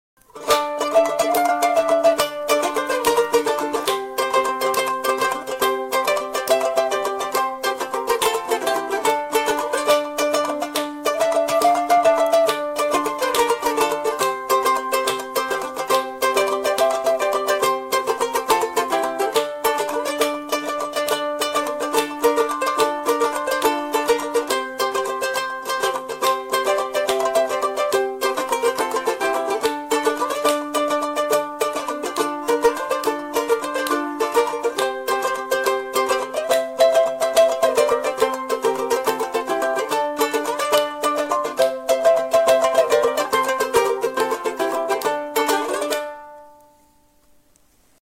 mélodie traditionnelle Ossète
à quatre voix